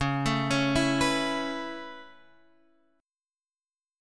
Dbm7#5 chord